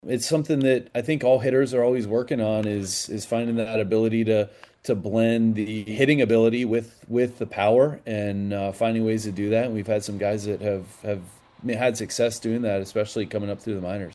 Kelly and Cherington spoke on a Grapefruit League conference call and both addressed the Andrew McCutchen situation.